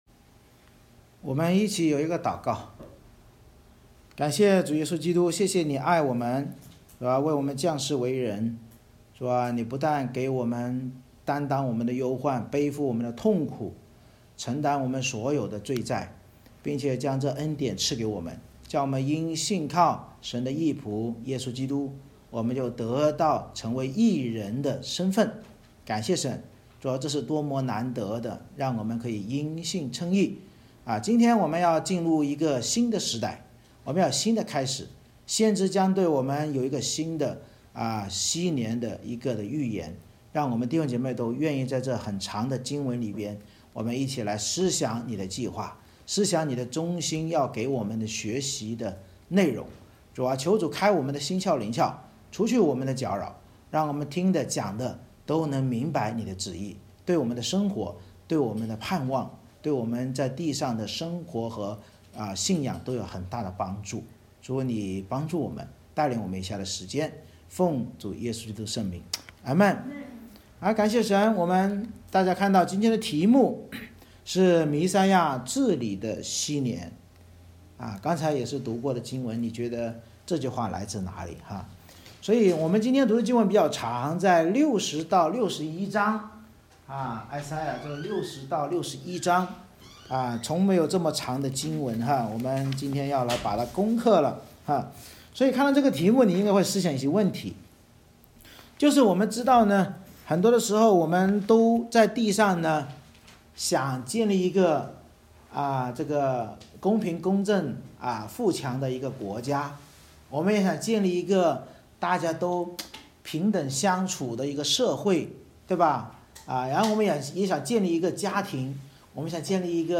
以赛亚书 Isaiah60:1-61:11 Service Type: 主日崇拜 神藉着先知预言受苦义仆所治理的恩年，使我们认识弥赛亚治理的禧年对真信徒是公平公义得医治释放的恩年，而对不信的仇敌将是报仇之日。